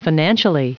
Prononciation du mot financially en anglais (fichier audio)